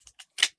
38 SPL Revolver - Close Barrel 002.wav